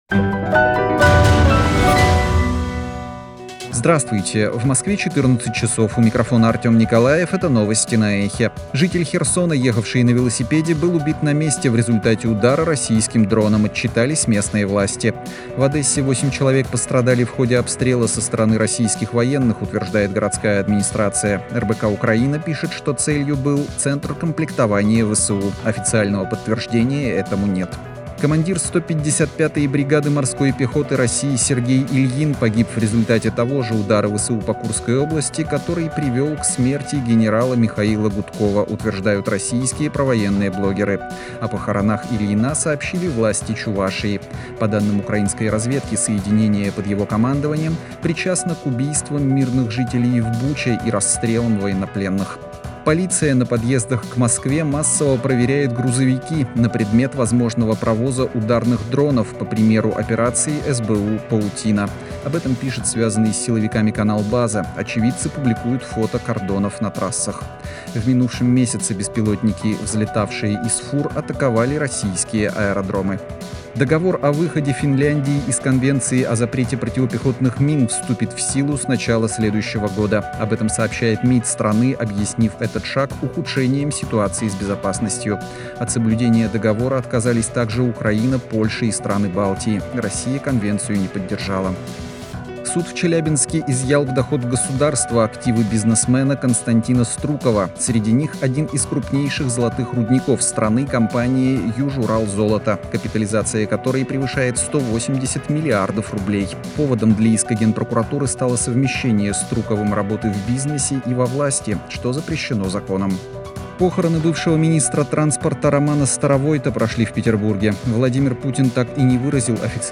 Новости 14:00